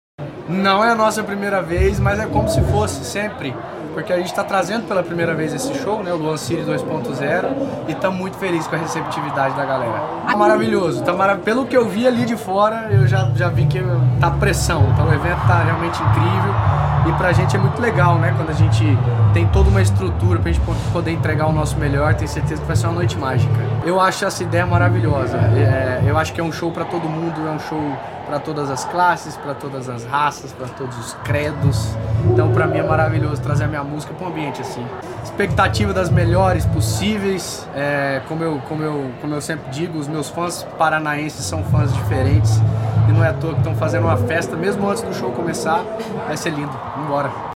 Sonora do cantor Luan Santana sobre o show no litoral paranaense